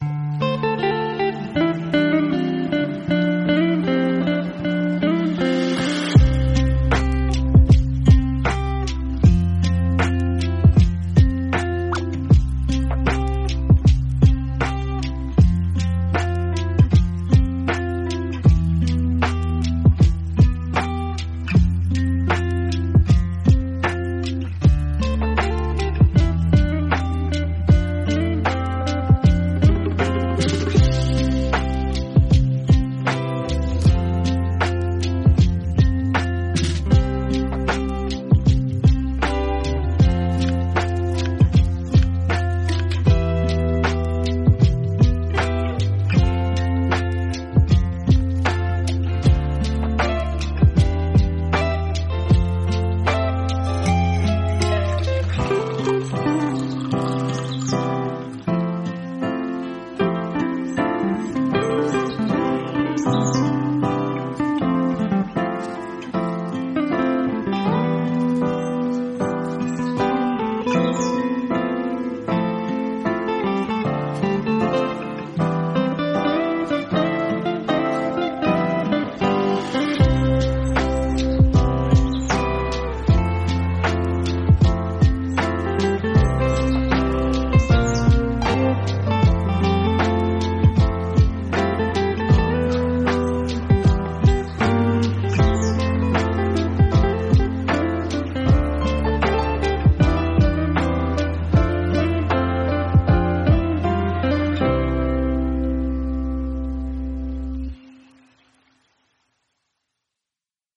calming